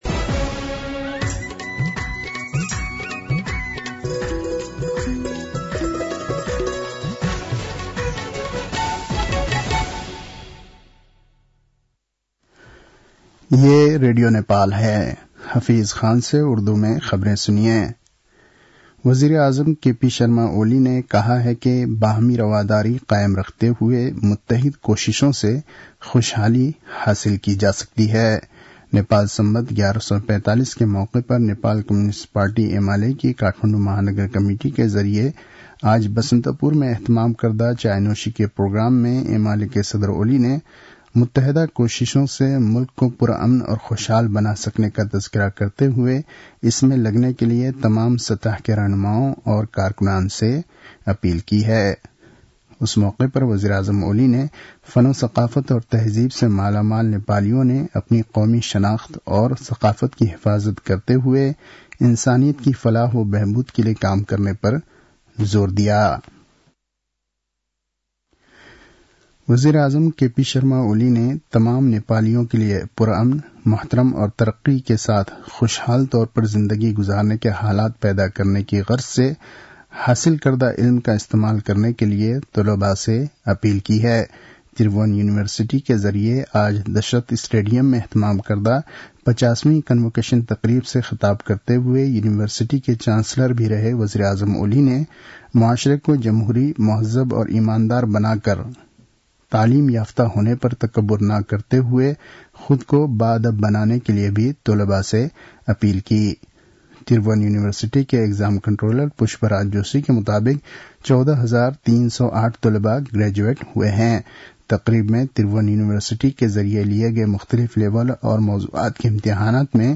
उर्दु भाषामा समाचार : १४ पुष , २०८१